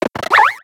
Cri de Colombeau dans Pokémon X et Y.